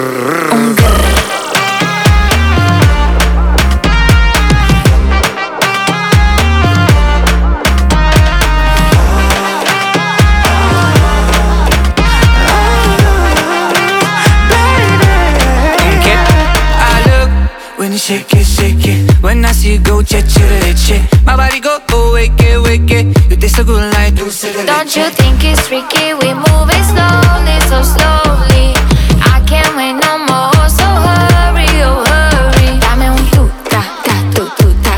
Pop Latino Latin